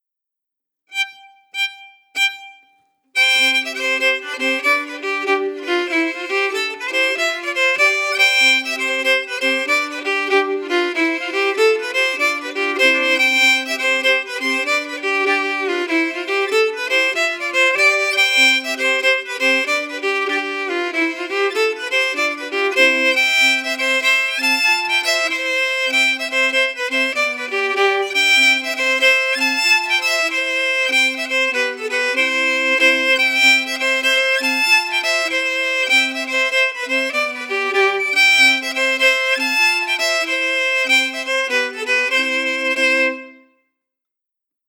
Key: C
Form: Québecois six-huit (Jig)
Genre/Style: Québecois six-huit
Quadrille-Beauharnois-melody.mp3